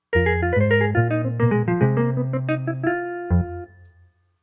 游戏胜利音效.mp3